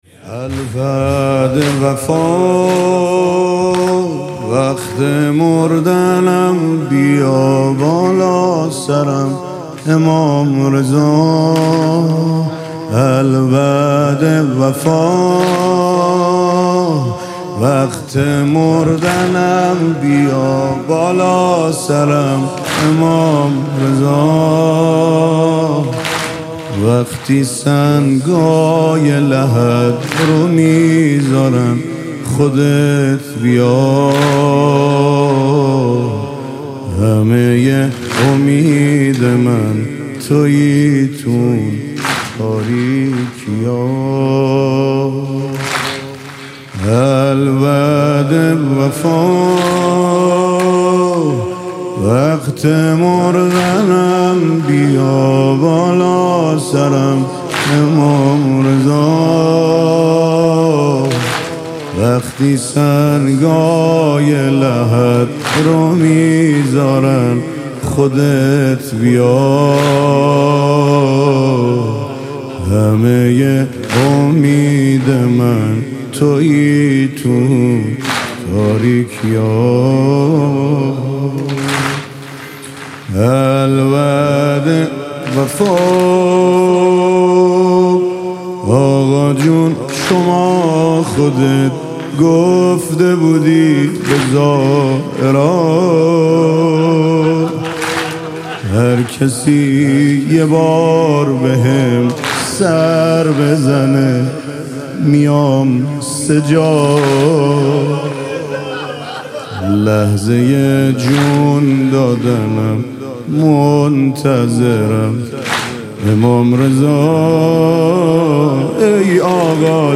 مذهبی
مناجات با امام رضا (ع)